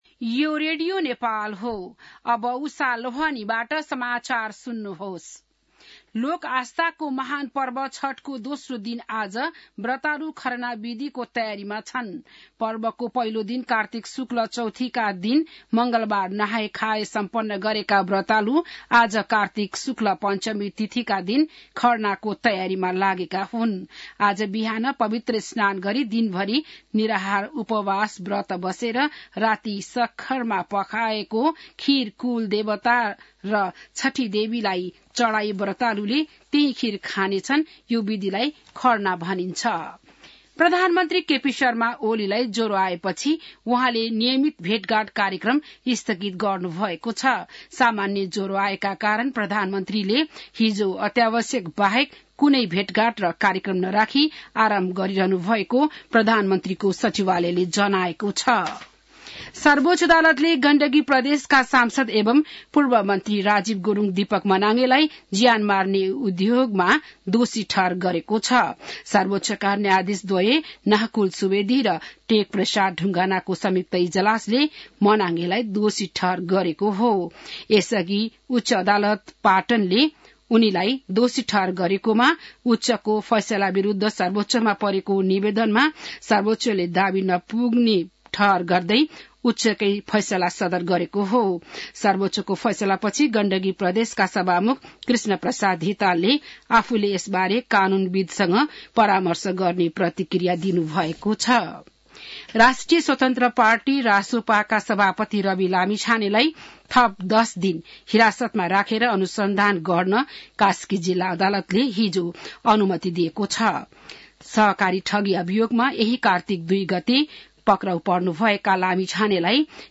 बिहान १० बजेको नेपाली समाचार : २२ कार्तिक , २०८१